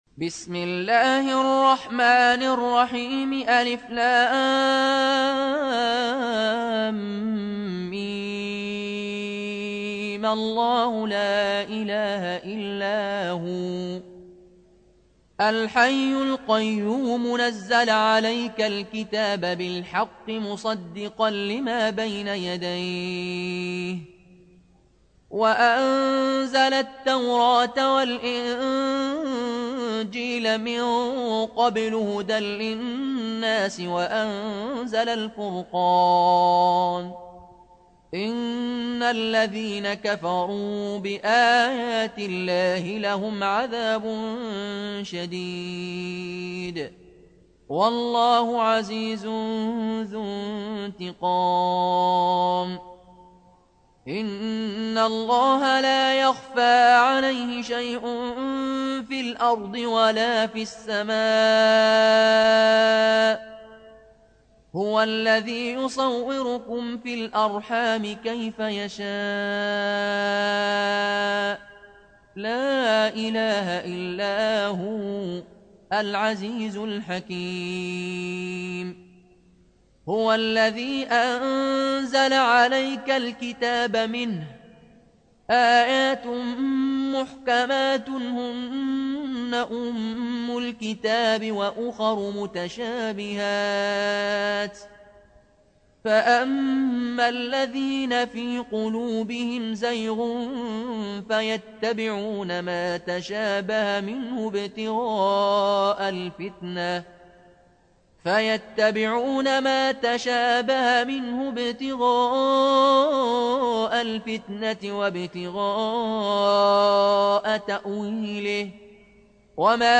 Riwayat Qaloon an Nafi